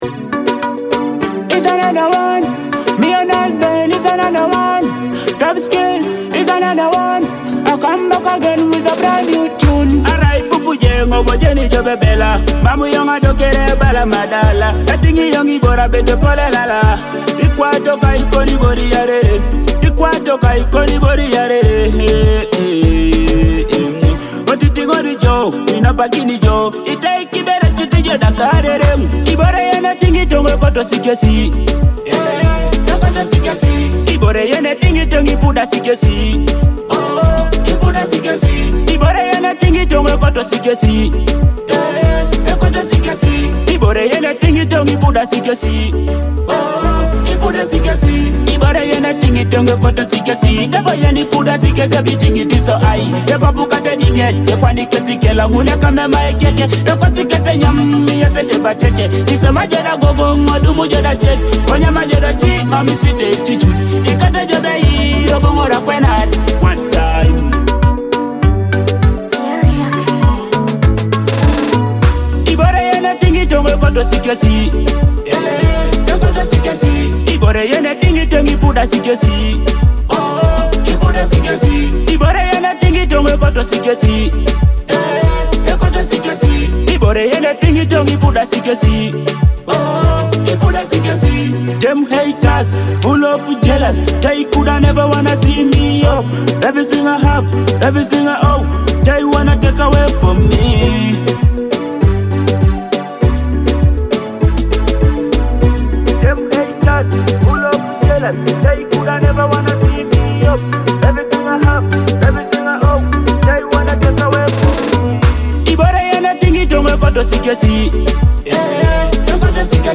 a fresh Teso hit